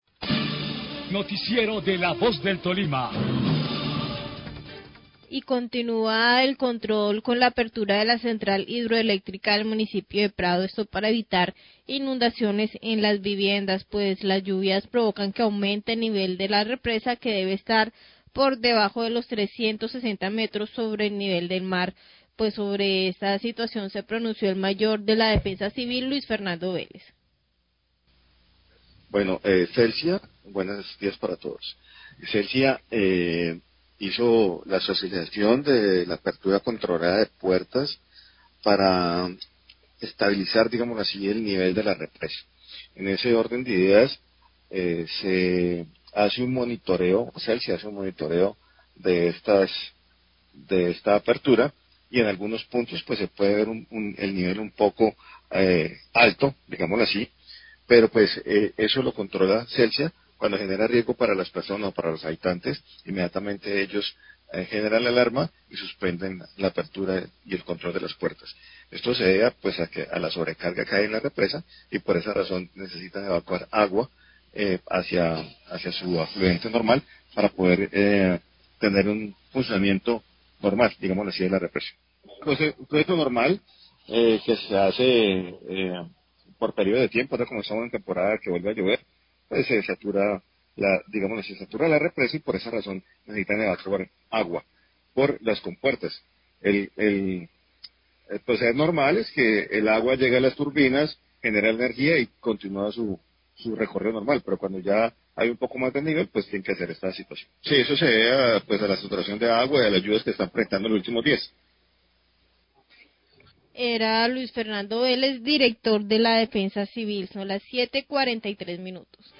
Radio
El director de la Defensa Civil del Tolima, My Luis Fernando Vélez,  presenta un reporte frente a la situación de la apertura gradual de las compuertas de la represa de Prado. Se refiere al continuo monitoreo de la situación por paret de Celsia para evitar que las comunidades ribereñas aguas abajo de los municipios del Prado y Purificación se vean afectadas.